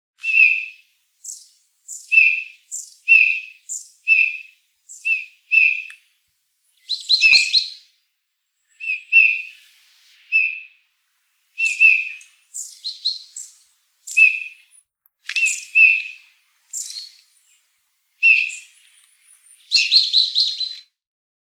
Gimpel Gesang
Ihre leisen, melancholischen Rufe unterscheiden sich kaum vom Männchen.
Gimpel-Gesang-Voegel-in-Europa.wav